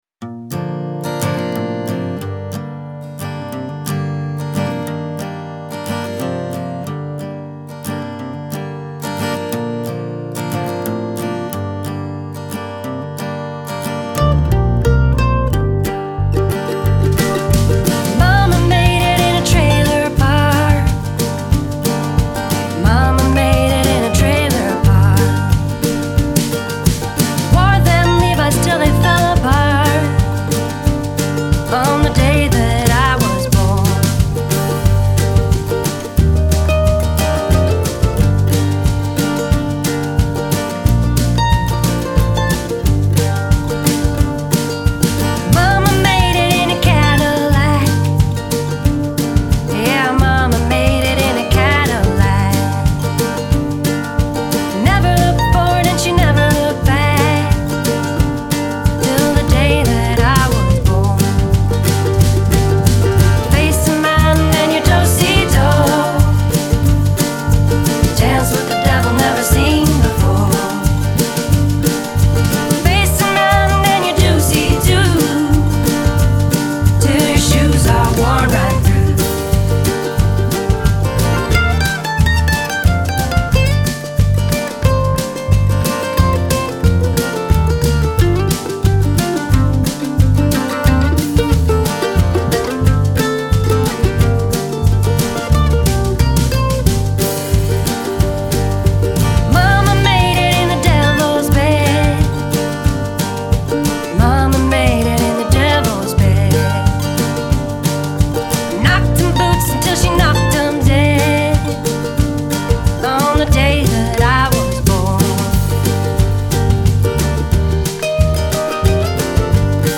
upbeat Americana blend
lead guitar
upright bass
mandolin
sweet harmonies
violin
alt-country and indie folk music